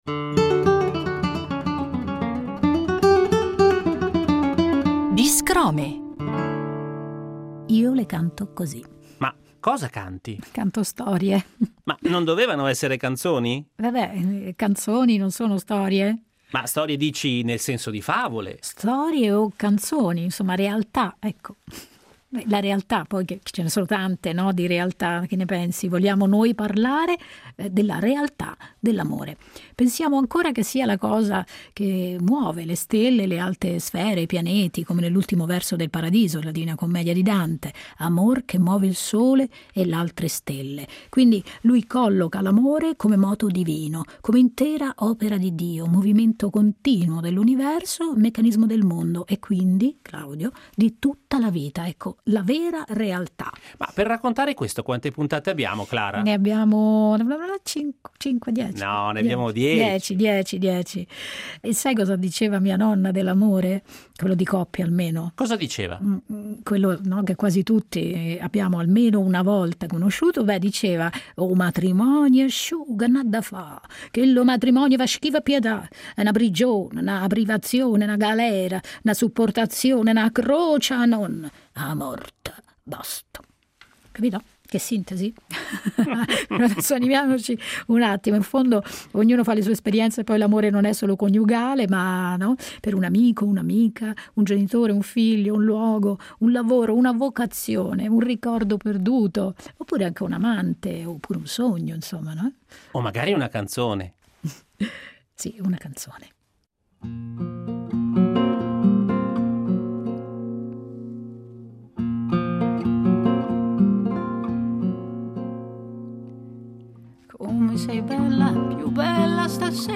porta in scena nel metodo dello spoken word
una poesia orale nel battito della musica.